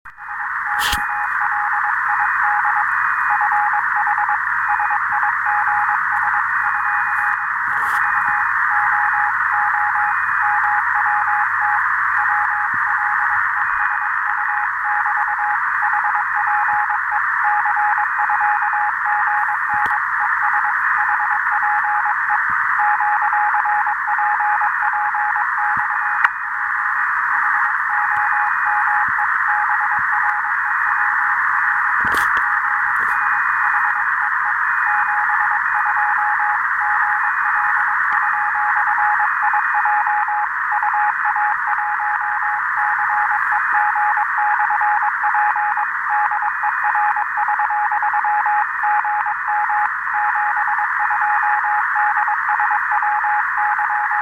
Поработал QRPP/p в лесах-полях на трансвертере, 400 мВатт, Delta, 14 мГц.